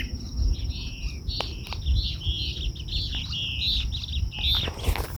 Fiofío Copetón (Elaenia flavogaster)
Nombre en inglés: Yellow-bellied Elaenia
Localización detallada: Costanera de Candelaria sobre arroyo Garupa
Condición: Silvestre
Certeza: Fotografiada, Vocalización Grabada
Fiofio-copeton.mp3